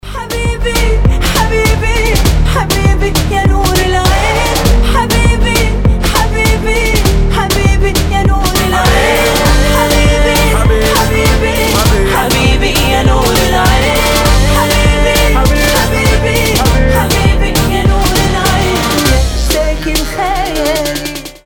• Качество: 320, Stereo
заводные
дуэт
Cover
восточные
RnB
арабские